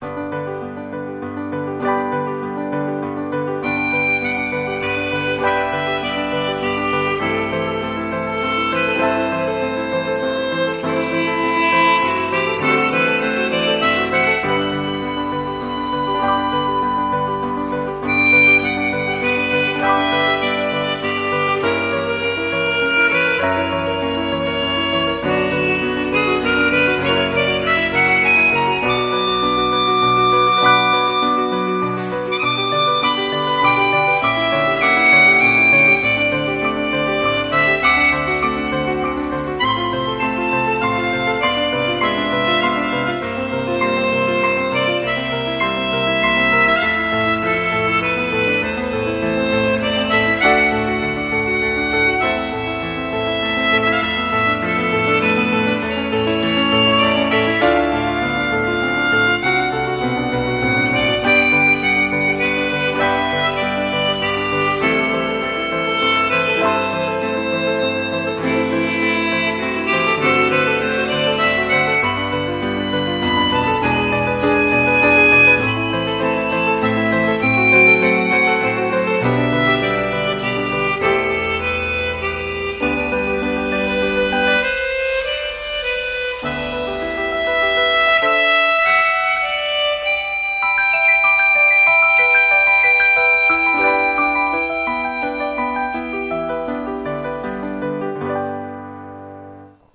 harmonica
Atualmente tem feito apresentações, tocando gaita, acompanhado com sequenciador.